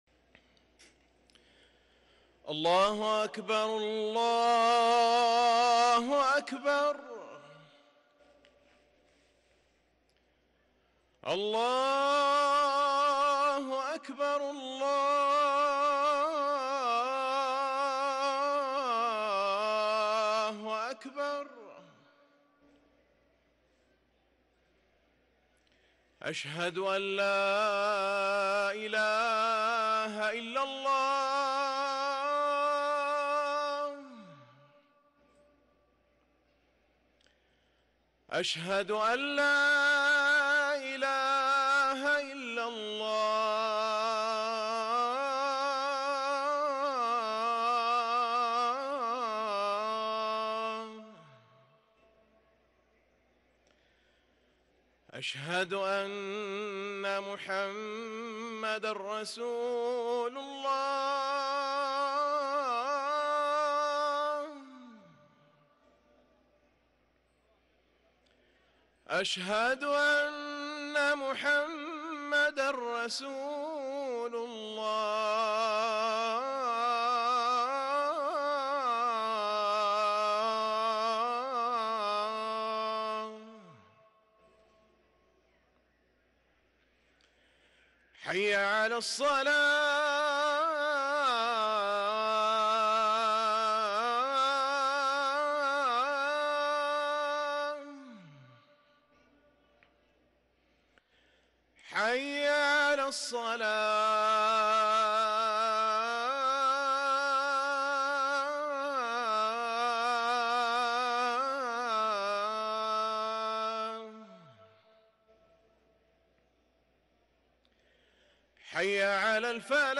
اذان المغرب